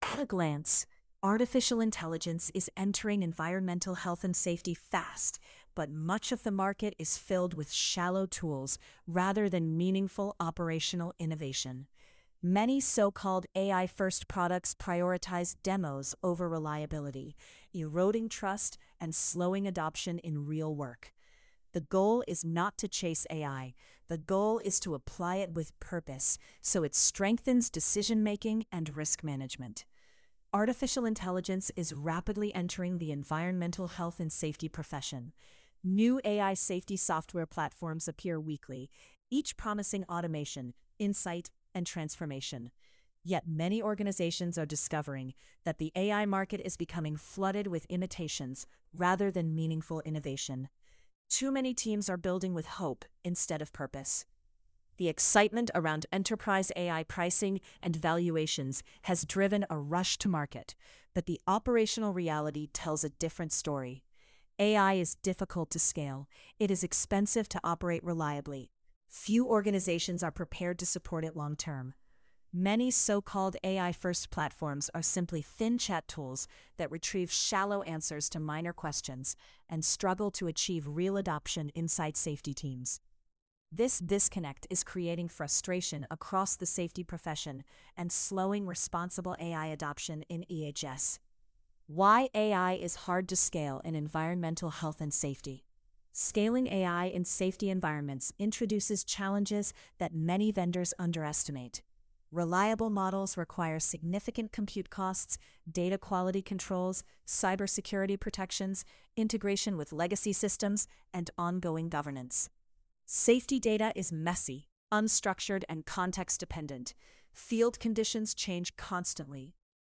sage_gpt-4o-mini-tts_1x_2026-01-29T04_02_48-638Z.wav